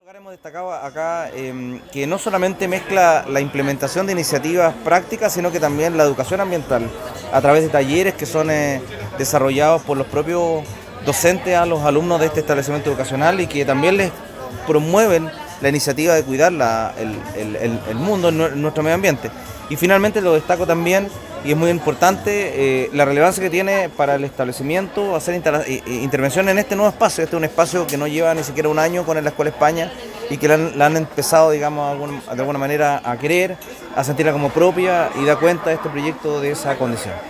Con presencia de autoridades provinciales y municipales, junto con representantes de la comunidad escolar contó la ceremonia de inauguración del equipo de reutilización de agua potable, proyecto al cual Escuela España postuló mediante concurso del fondo “Contigo en cada gota” de Esval.
“Destacamos la mezcla de iniciativas prácticas y también la educación ambiental, mediante talleres desarrollados por los propios docentes y que promueven la iniciativa de cuidar nuestro mundo y destacó la relevancia que tiene para el establecimiento realizar intervenciones como esta, a sentir como propio el espacio” Comentó el Gobernador Provincial de Los Andes, Sergio Salazar, dando especial importancia al sello ambiental del plantel.
Governador-Sergio-Salazar-EDITADO.m4a